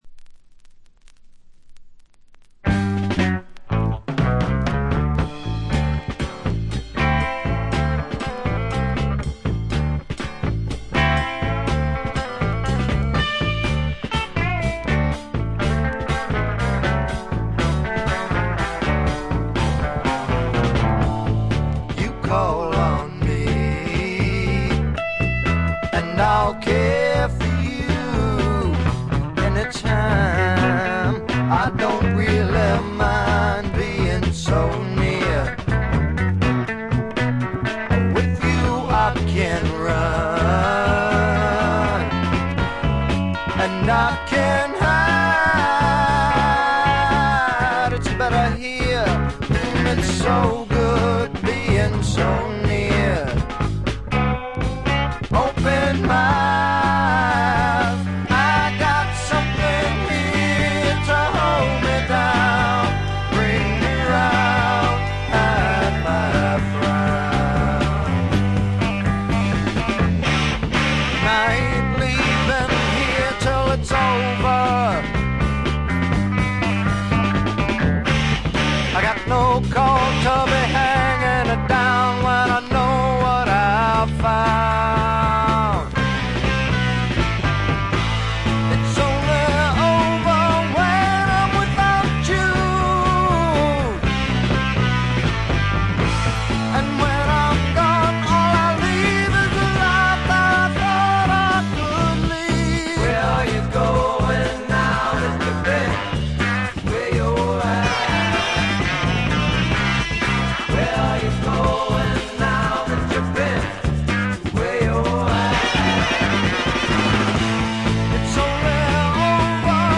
静音部でのバックグラウンドノイズや軽微なチリプチはありますが、気になるようなノイズはないと思います。
60年代的なサイケ要素がところどころで顔を出します。
試聴曲は現品からの取り込み音源です。
Recorded At - Record Plant, Los Angeles